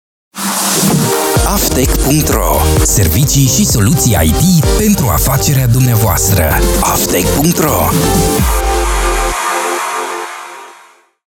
Promos
Natural and energetic style can breathe life into any script!
Styles: New, Contemporary, Clear, Deep, Hard Sell, Soft Sell, Sincere, Engaging, Urban